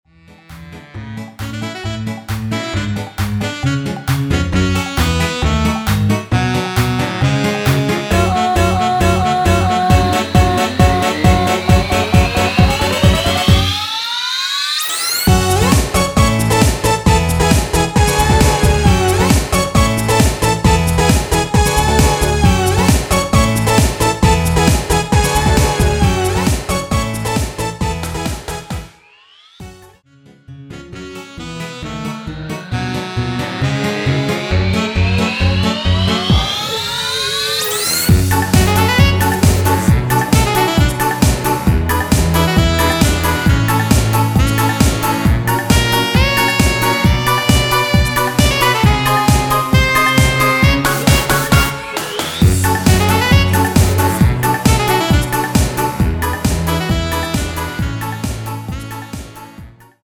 코러스가 거의 없어서 일반 코러스MR가격의 50% 가격으로 판매합니다..(미리듣기 참조)
F#m
앞부분30초, 뒷부분30초씩 편집해서 올려 드리고 있습니다.